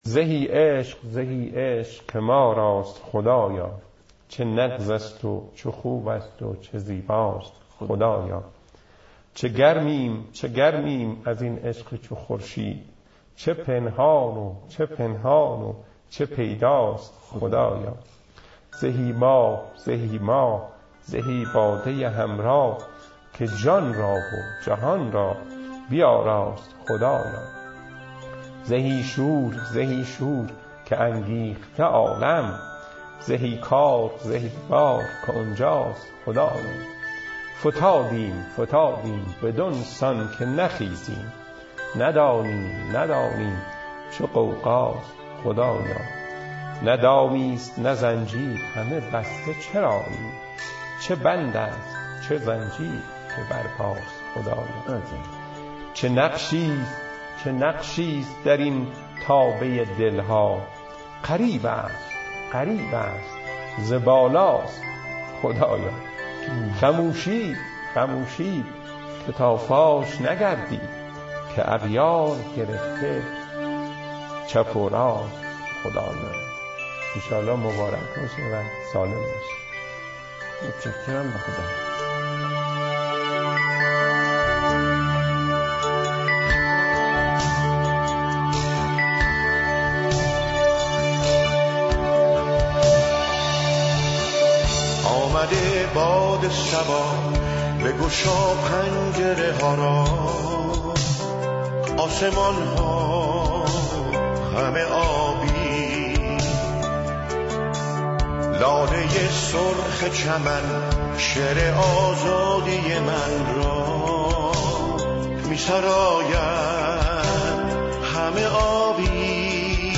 مولاناخوانی